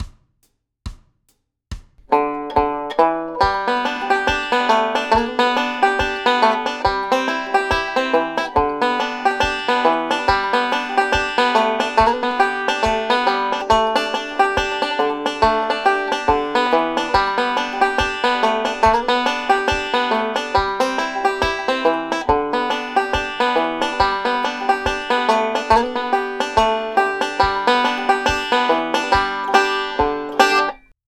introduces the forward reverse roll